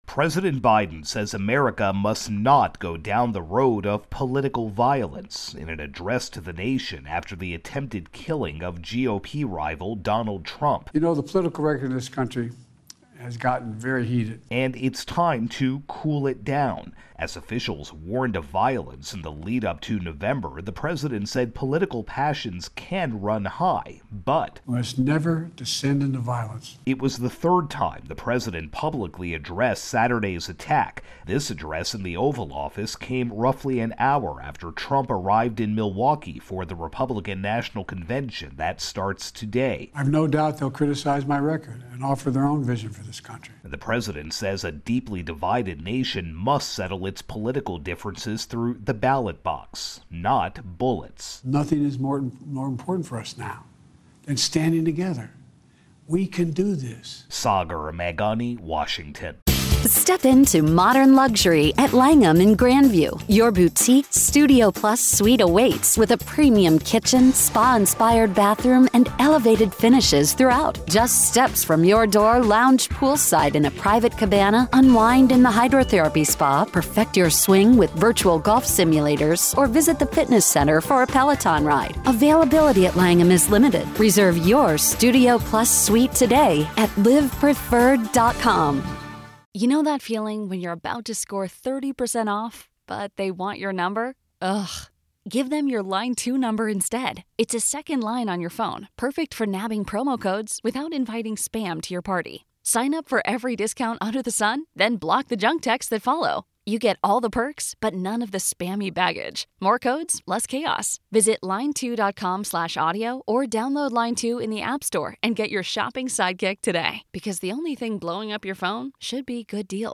In prime-time address, Biden warns of election-year rhetoric, saying 'it's time to cool it down'
President Biden says America must not go down the road of political violence, in an address to the nation after the attempted killing of GOP rival Donald Trump.